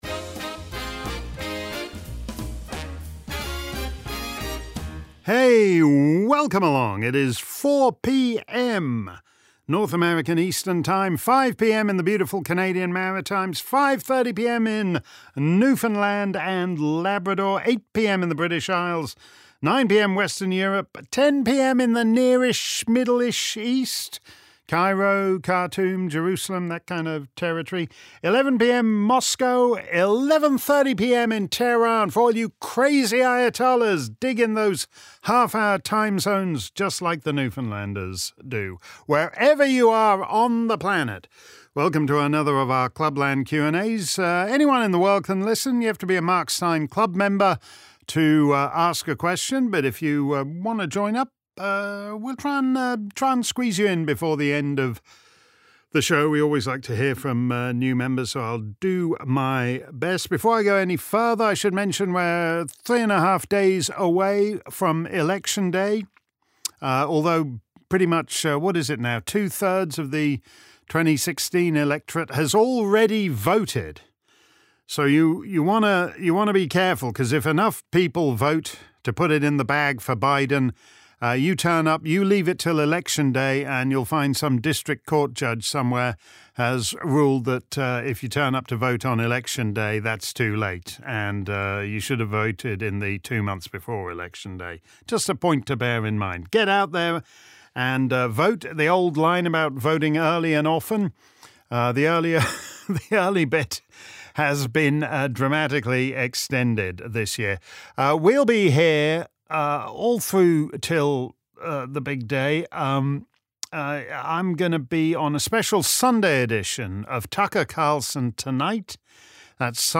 If you missed our livestream Clubland Q&A on Friday afternoon, here's the action replay.